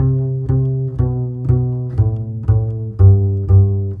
Rock-Pop 11 Bass 07.wav